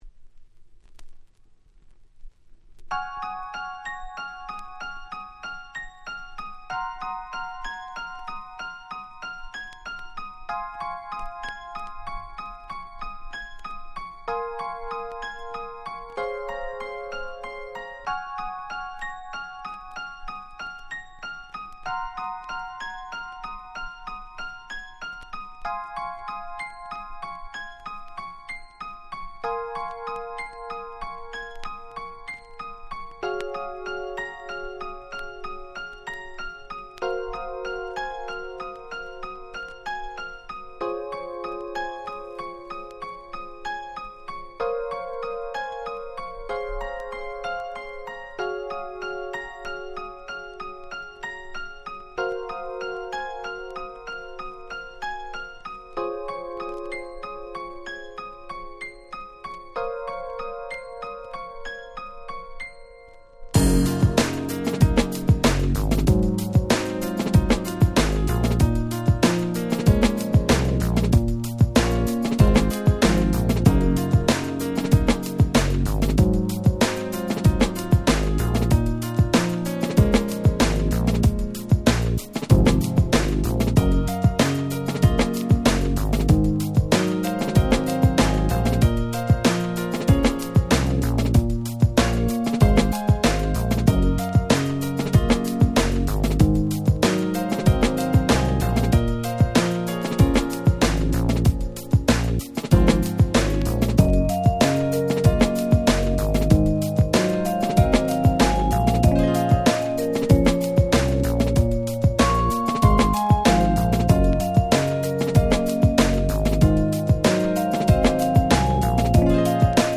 07' Very Nice Break Beats !!